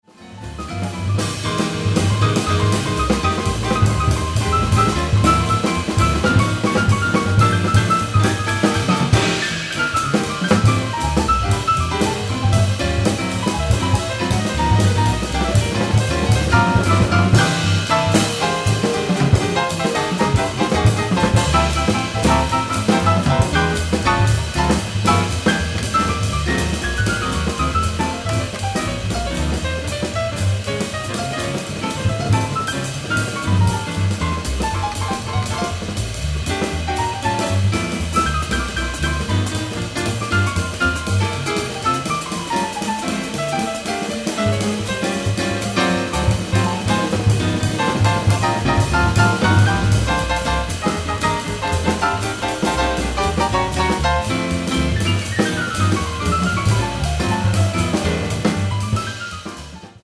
piano
acoustic bass
drums
post-bop setting